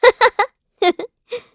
Hilda-Vox-Laugh_kr_b.wav